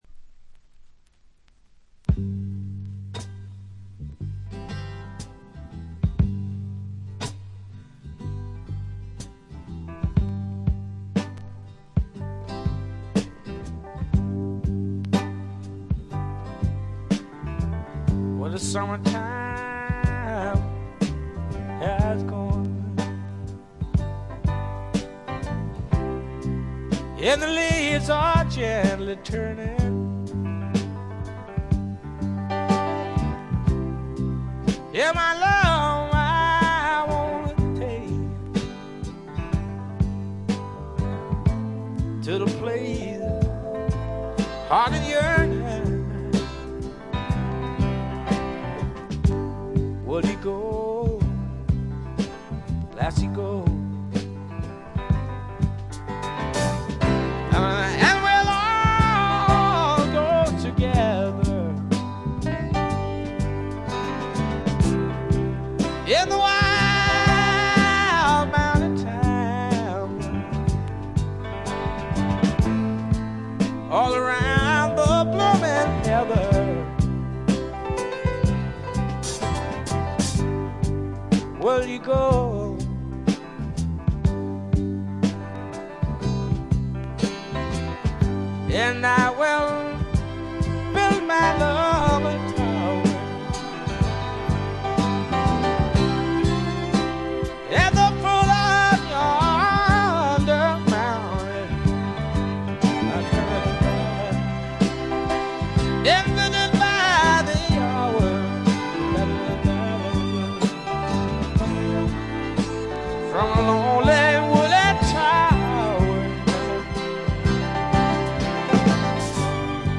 acoustic guitar, vocals